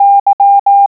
Click on a letter, number, or punctuation mark to hear it in Morse code.